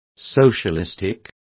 Complete with pronunciation of the translation of socialistic.